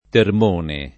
[ term 1 ne ]